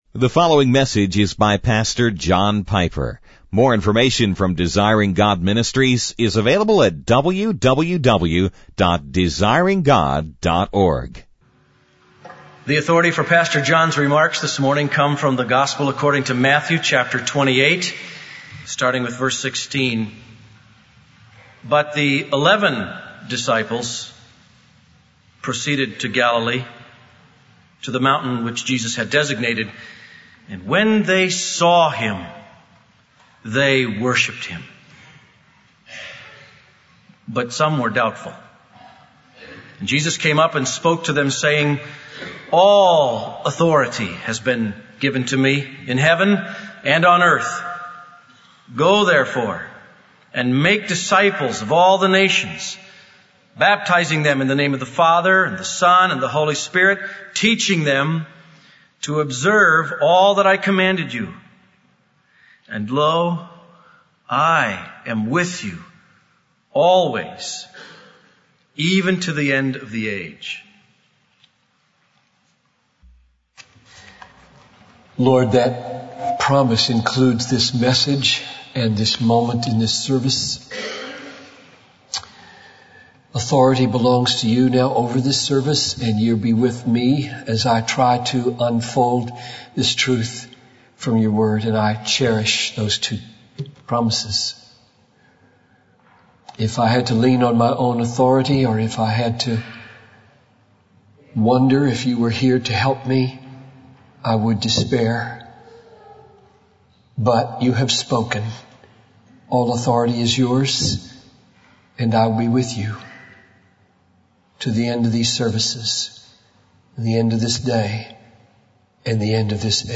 In this sermon, the speaker discusses the crucifixion of Jesus and its significance. He emphasizes that the crucifixion was not an accident, but part of God's plan. He highlights the verse John 3:16, stating that God gave his only son as a sacrifice for the world.